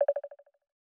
End Call3.wav